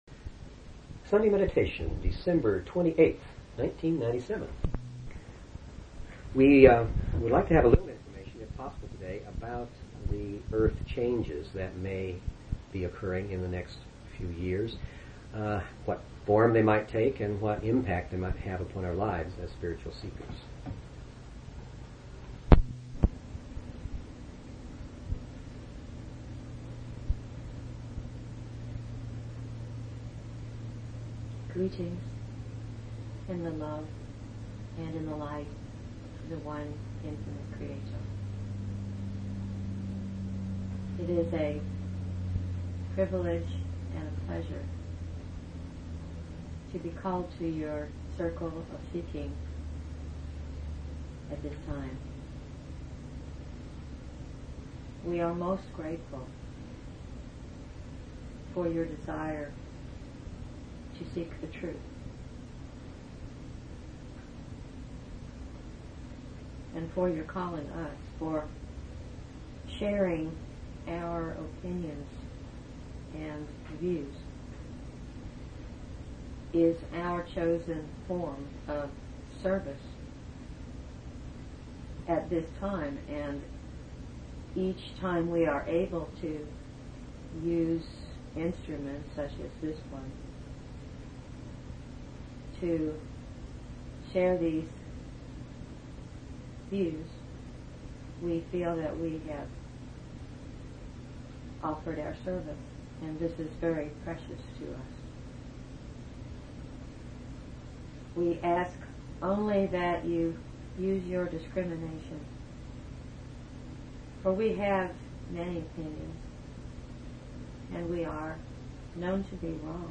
Talk Show Episode, Audio Podcast, LLResearch_Quo_Communications and Courtesy of BBS Radio on , show guests , about , categorized as